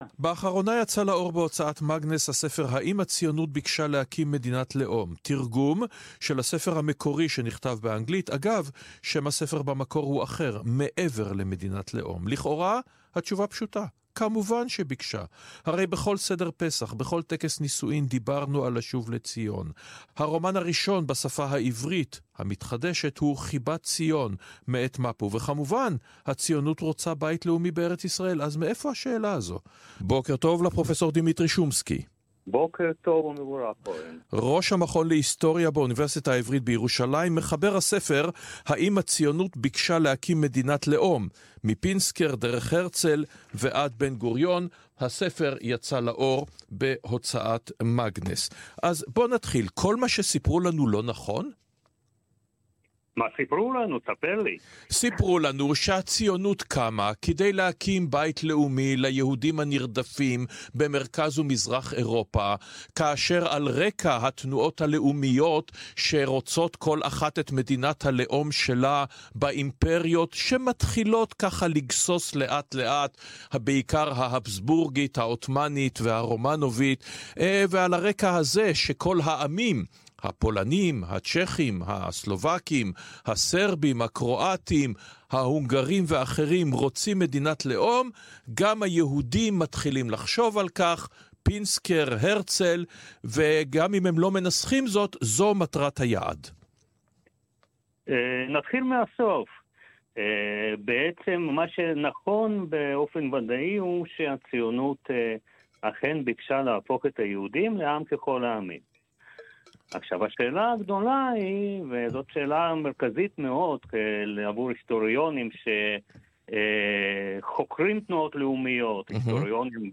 ריאיון